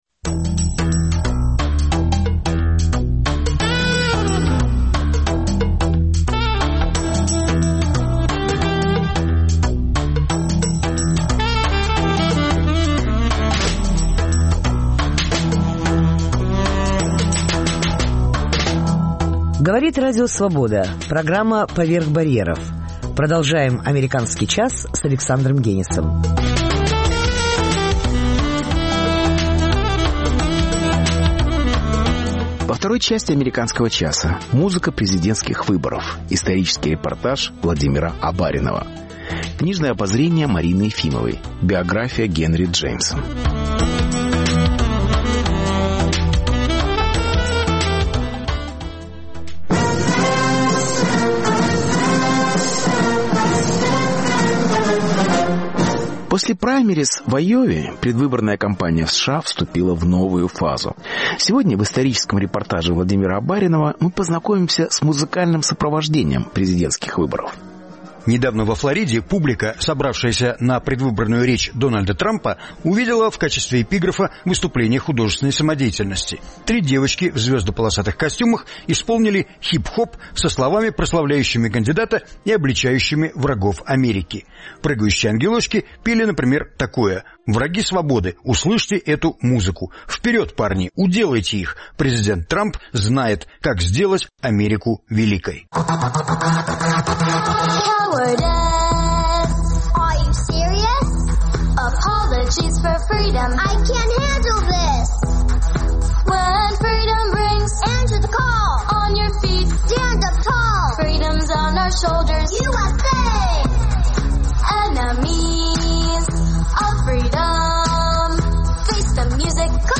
Музыка президентских выборов. Исторический репортаж